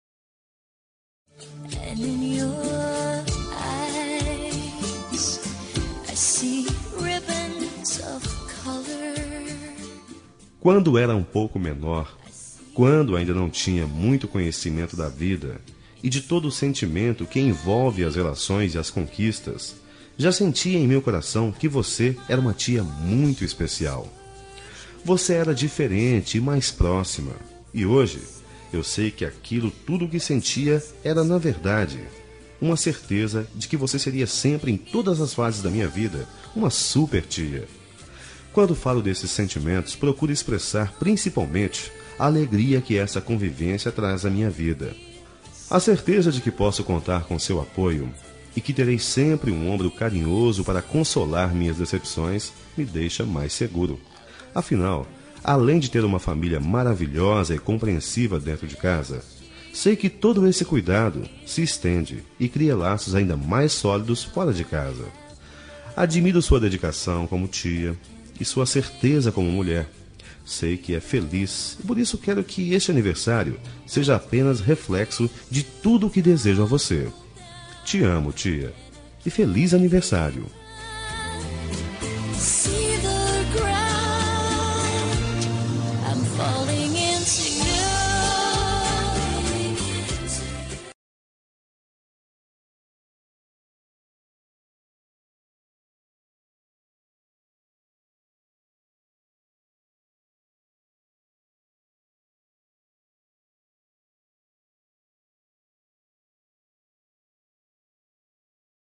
Aniversário de Tia – Voz Masculina – Cód: 941